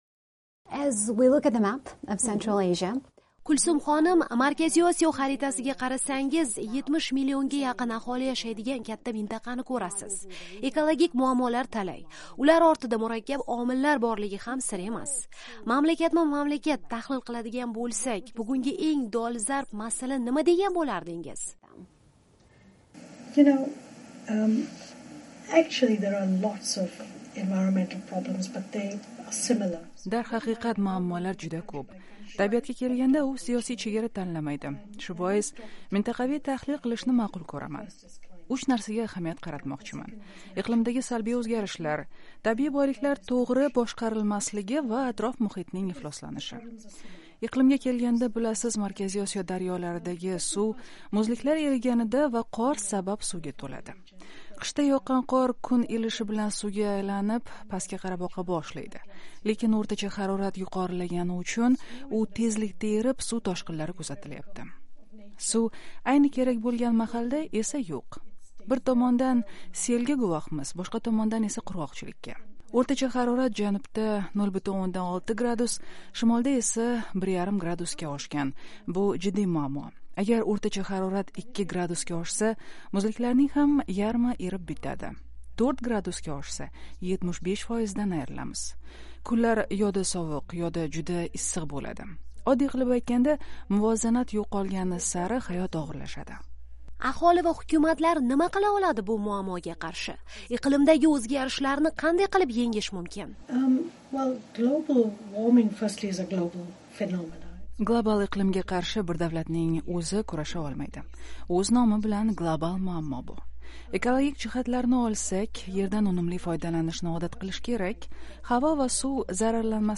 muloqot